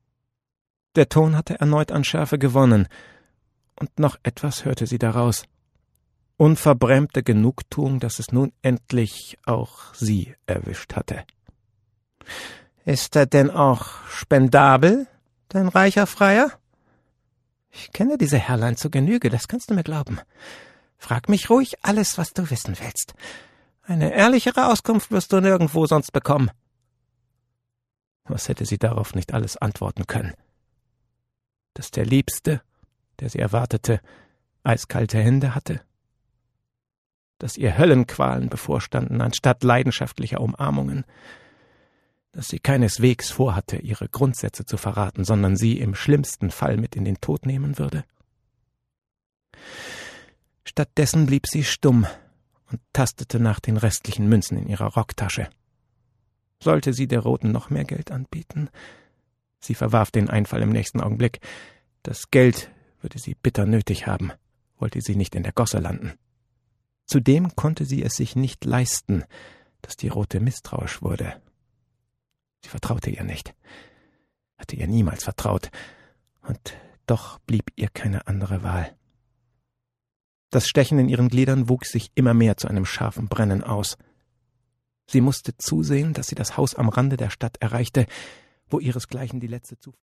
Die Pestmagd - Brigitte Riebe - Hörbuch